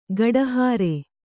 ♪ gaḍahāre